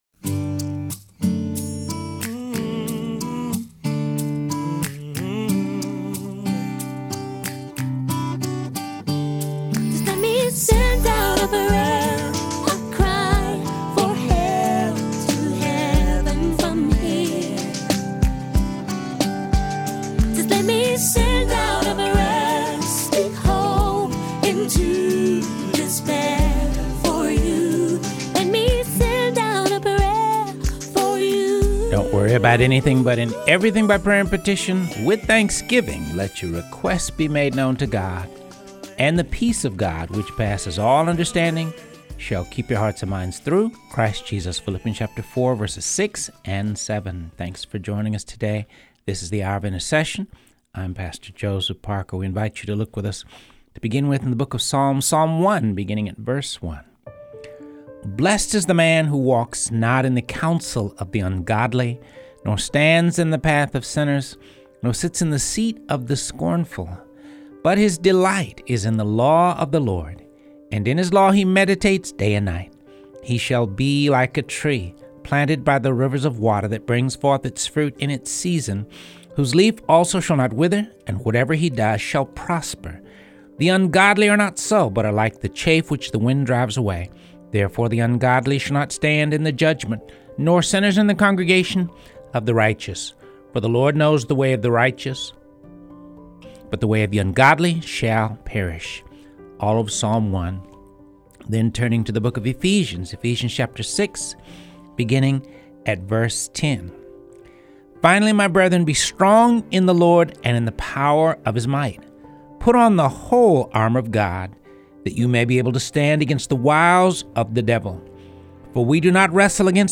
Verse by verse